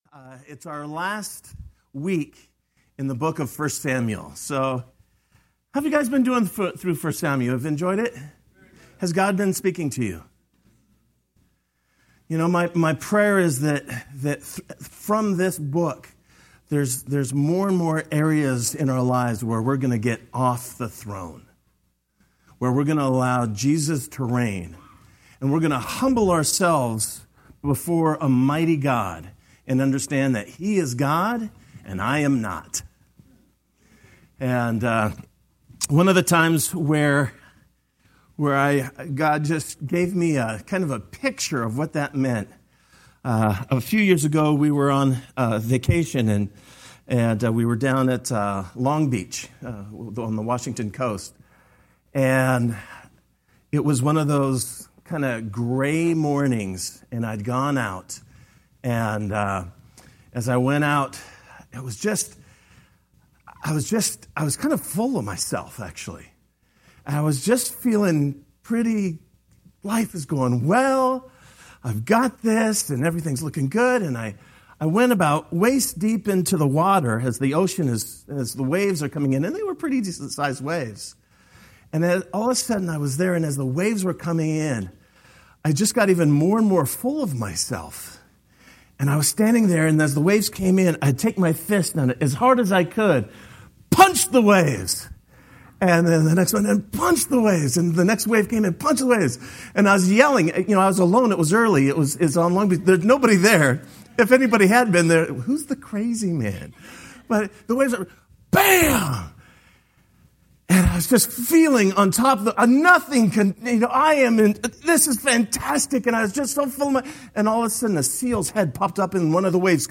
Sermon-Oct-30.mp3